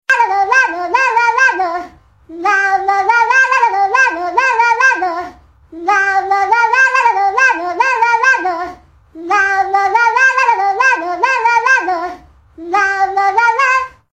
หมวดหมู่: เสียงเรียกเข้า
ringtone phone opening but by a cat remix…